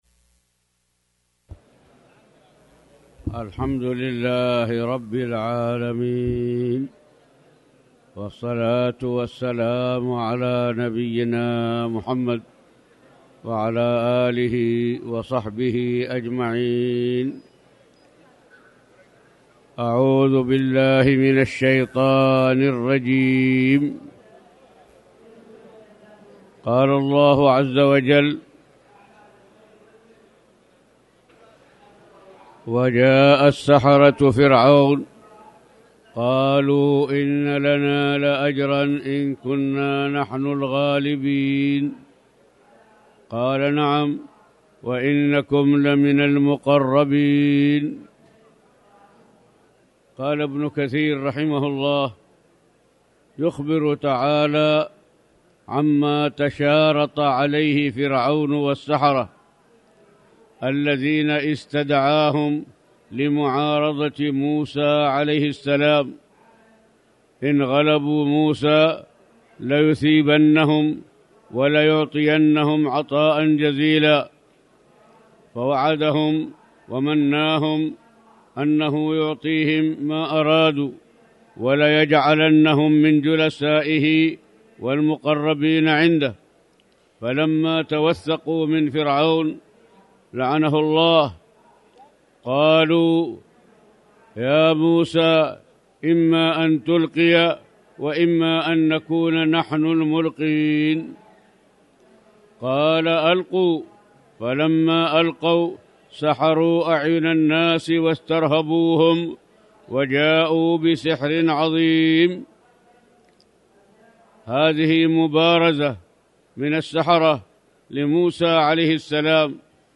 تاريخ النشر ٦ رجب ١٤٣٩ هـ المكان: المسجد الحرام الشيخ